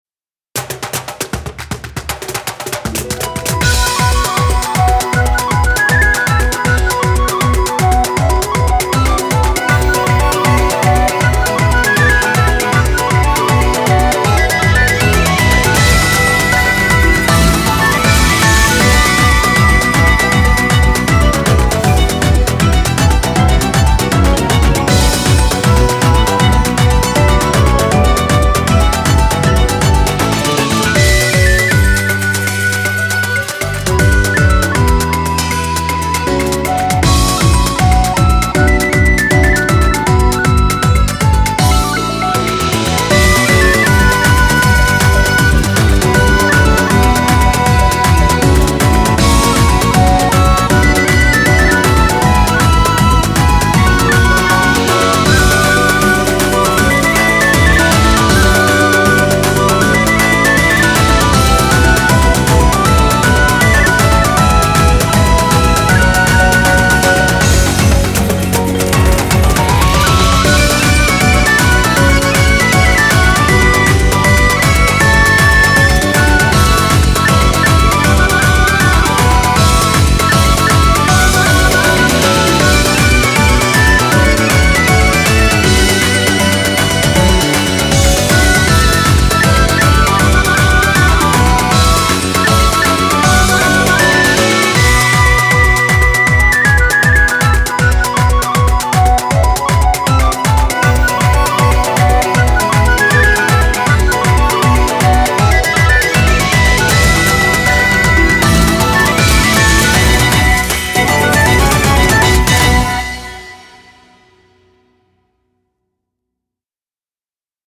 BPM158
Audio QualityPerfect (High Quality)
Follow the 12th notes in this celtic song!